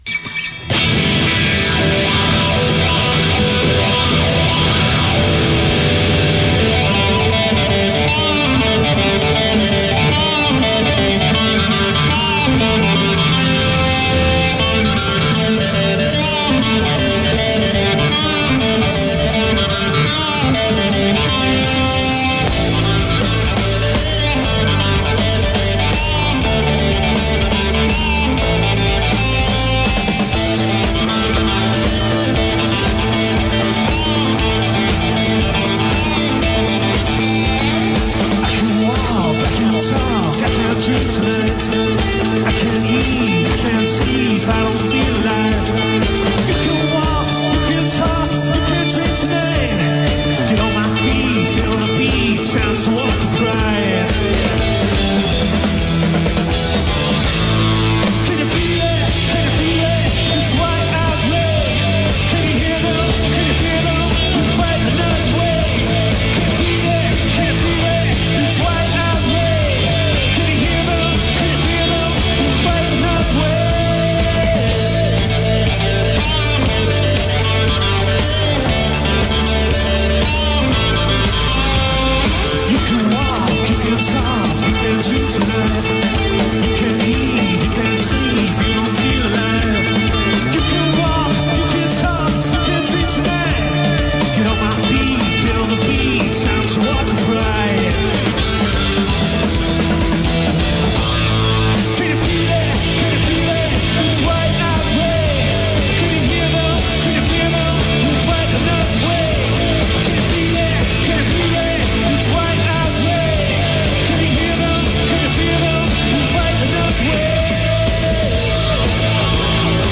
indie rock quartet
fizzing with energy and conviction.